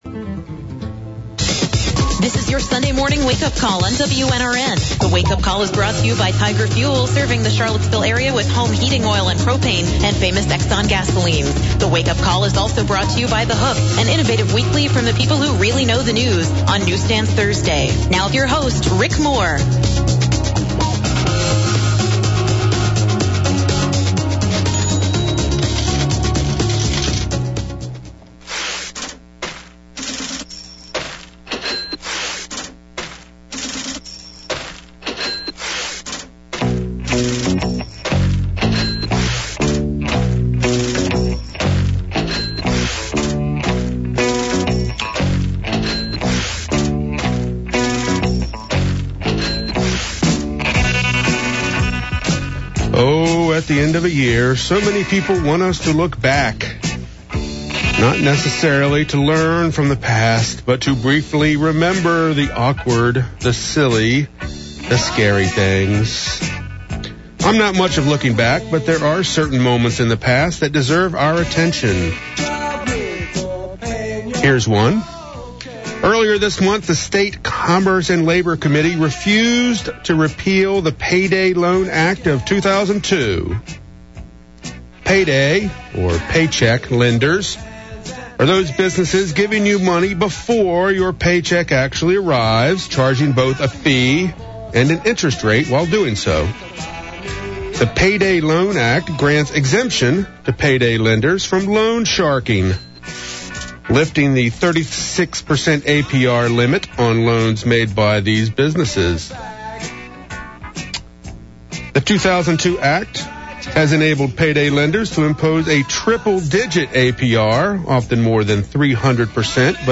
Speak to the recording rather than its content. at the WNRN studios